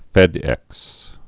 (fĕdĕks)